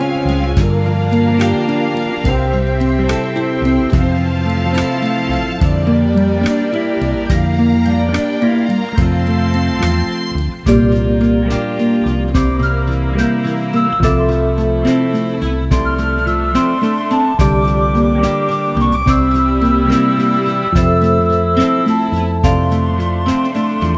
no Backing Vocals Soul / Motown 2:18 Buy £1.50